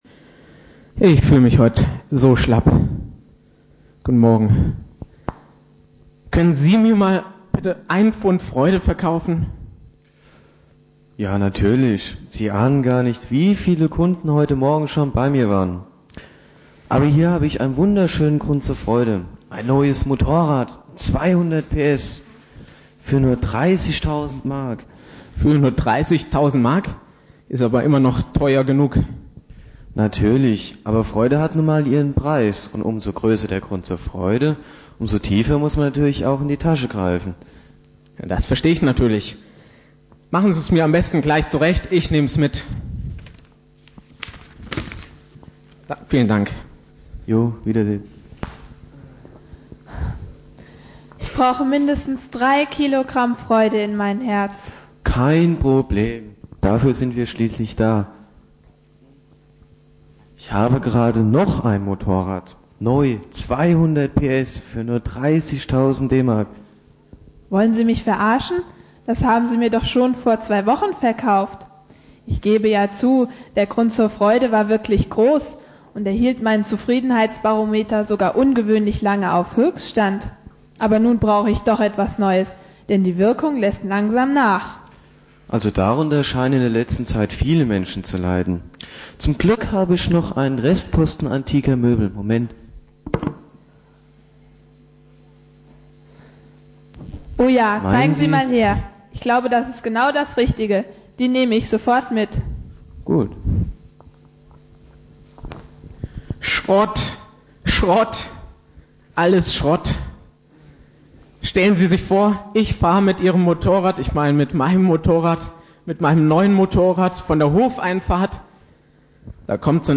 "Freude" - Anspiel und Predigt im Rahmen eines Jugendgottesdienstes